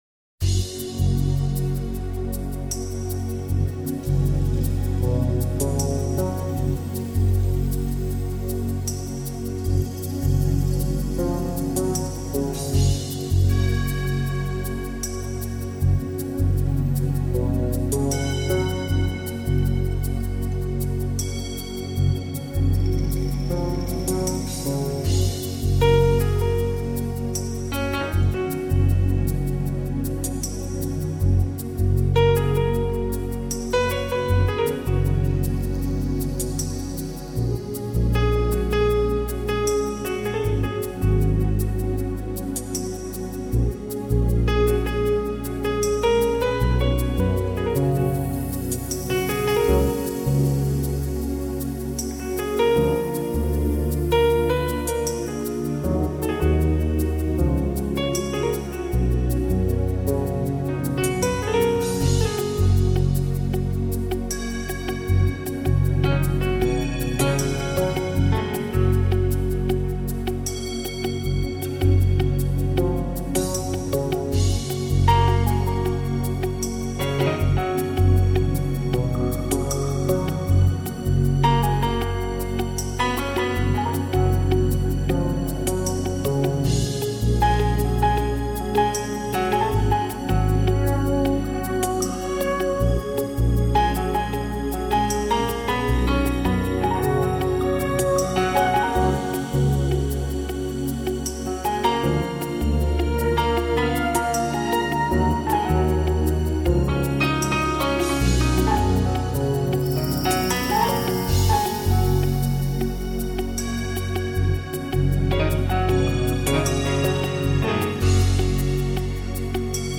钢琴演奏
这张专辑给我的感觉就是简朴而醇美，没有过多的修饰的原味之美，固然也拥有电子键盘的伴奏，但是更主要的是钢琴